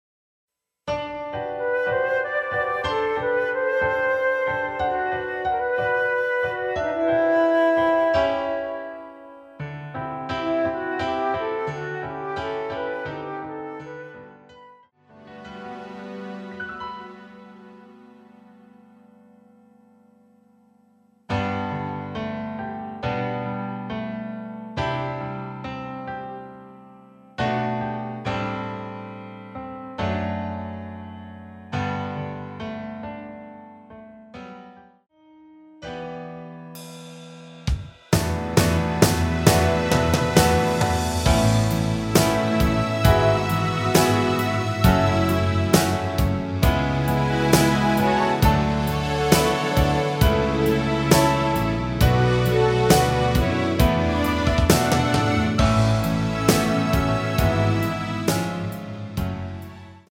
키 G
원곡의 보컬 목소리를 MR에 약하게 넣어서 제작한 MR이며